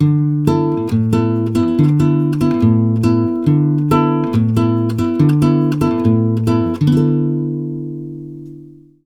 140GTR D7  4.wav